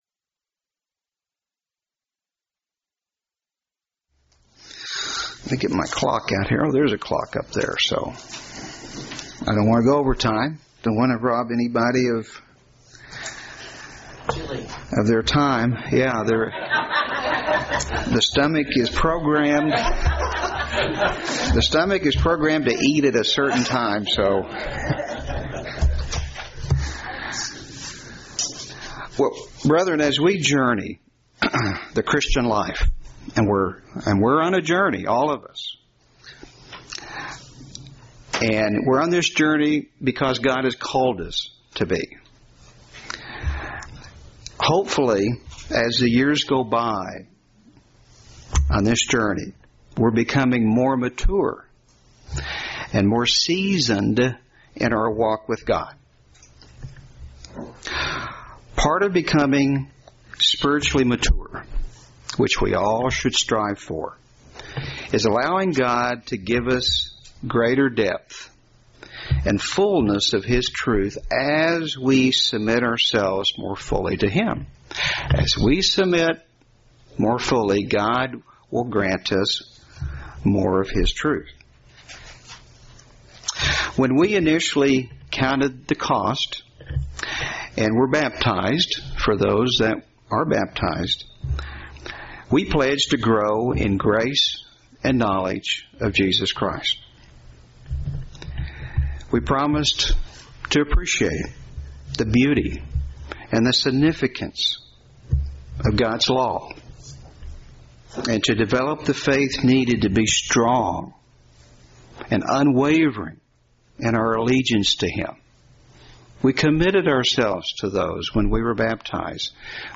Given in Northwest Arkansas
UCG Sermon Studying the bible?